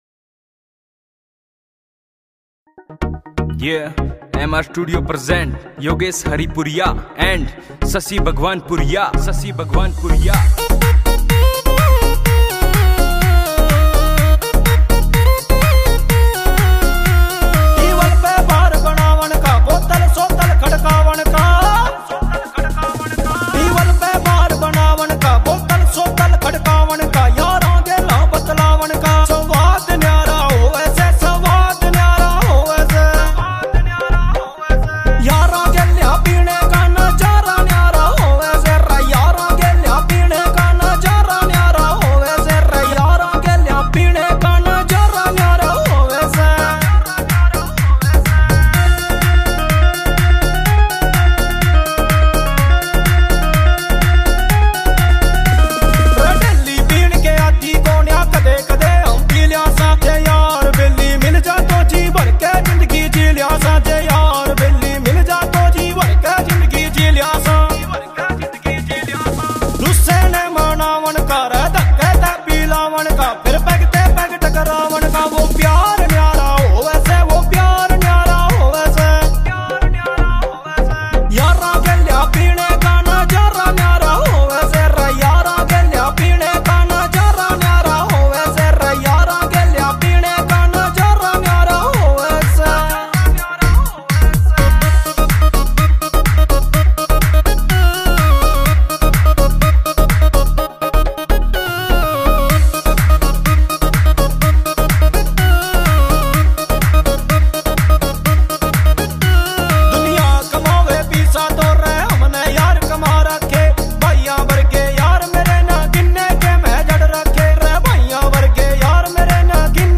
[ Haryanvi Songs ]